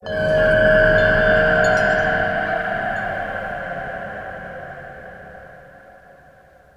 Bell Pad.wav